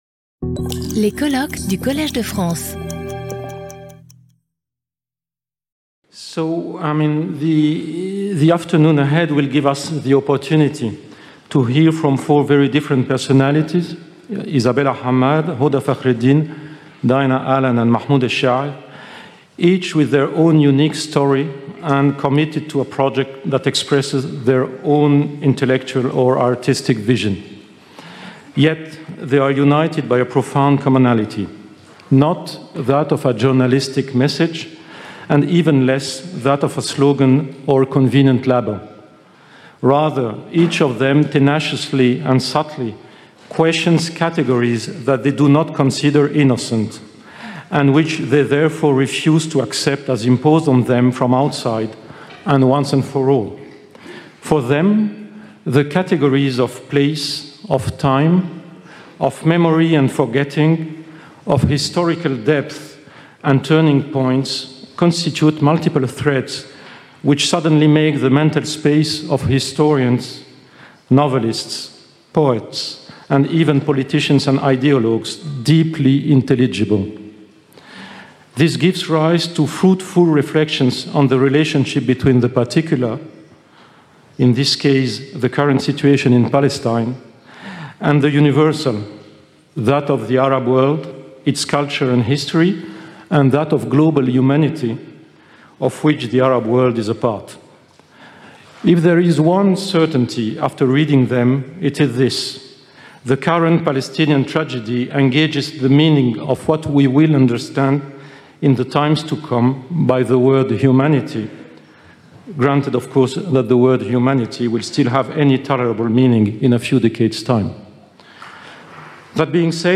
Skip youtube video player Listen to audio Download audio Audio recording Cette vidéo est proposée dans une version doublée en français.